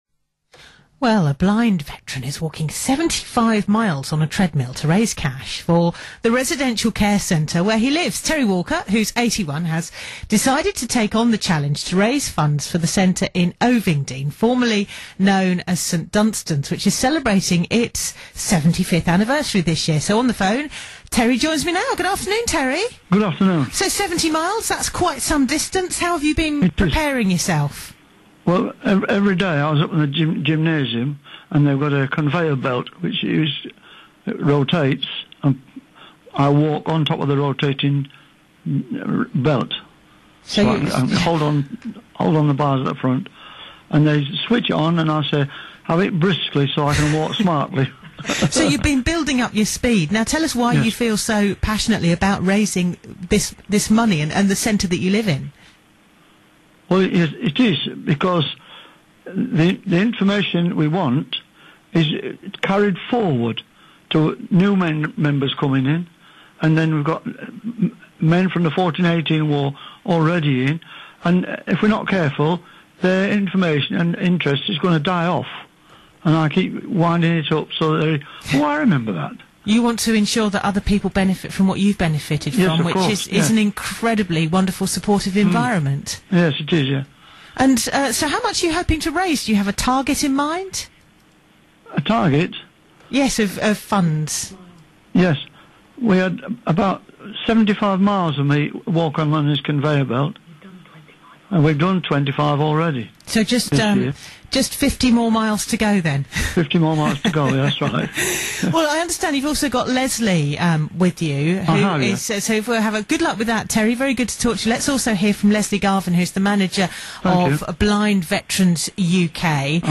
BBC Radio Sussex Interview